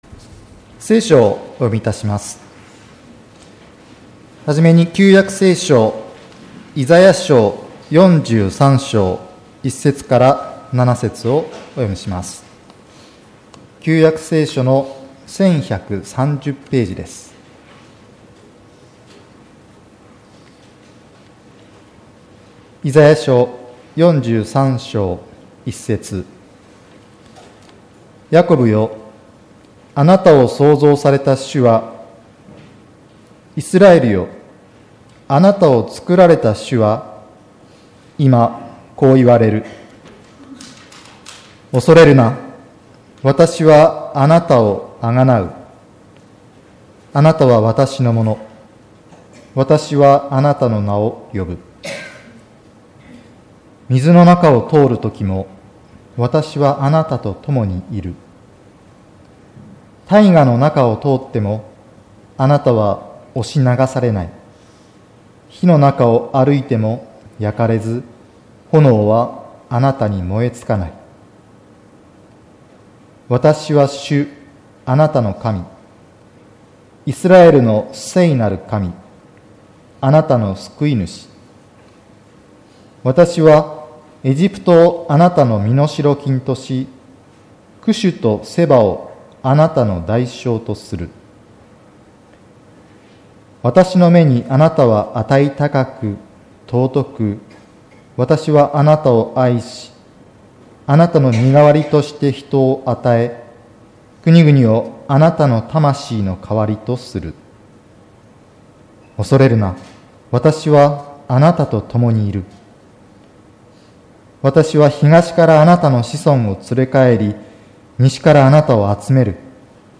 受難節第４主日礼拝
聖書朗読：